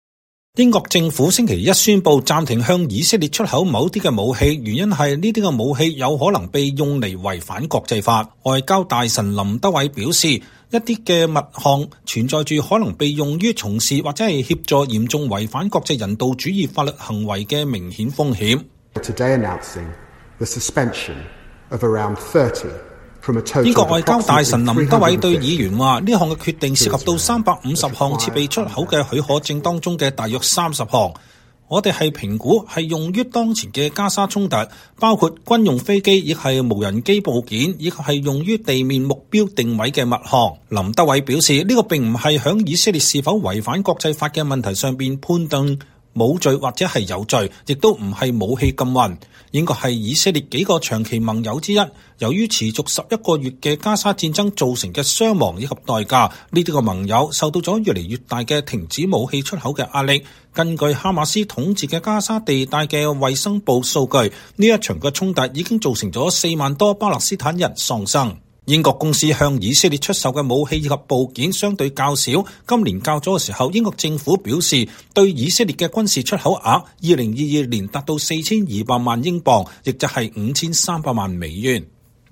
英國議會記錄部門播放影片的截圖顯示，英國外交大臣林德偉在議會就對以色列軍售問題發表演說。